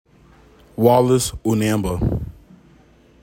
Pronunciation: WAL iss oo NAM buh